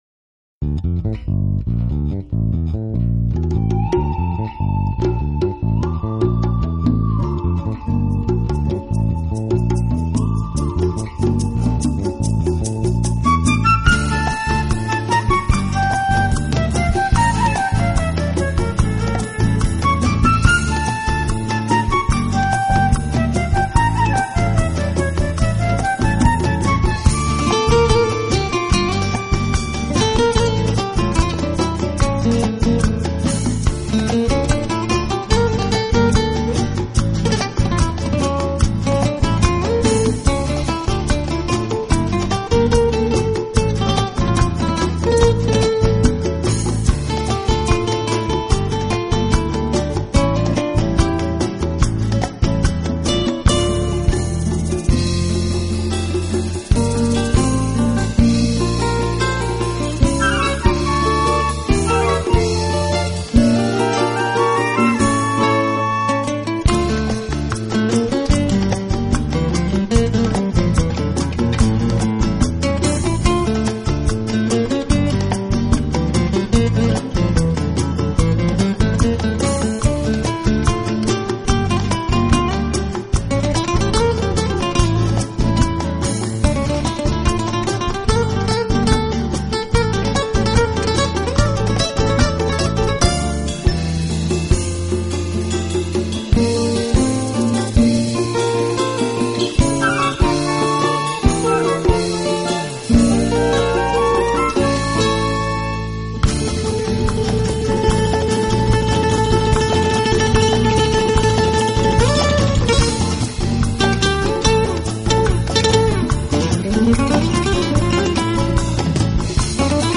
【佛拉明戈吉他】
音乐类型：flamenco